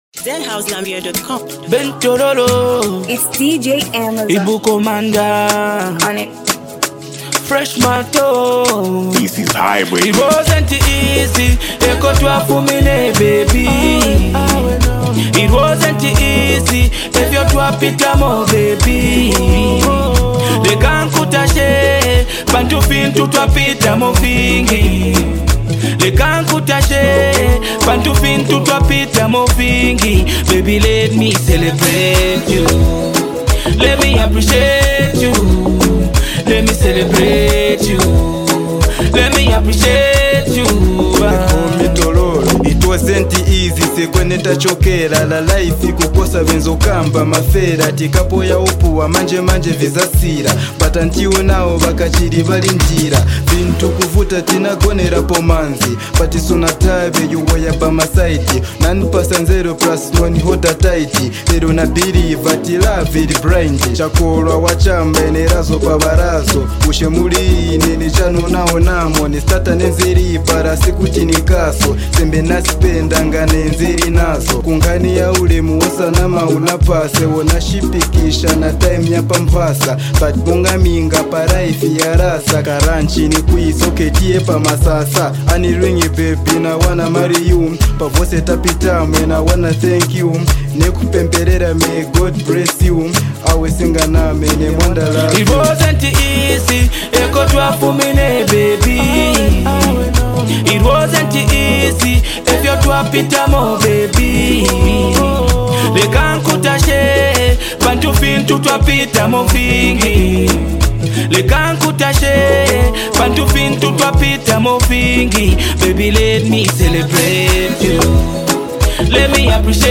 a feel-good track